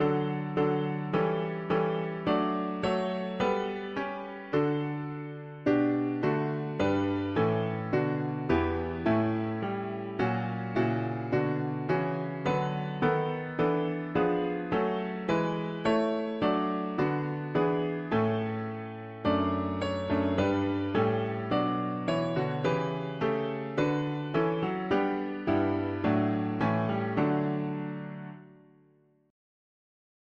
Alleluia, allelu… english christian 4part chords
Key: D major Meter: 87.87.87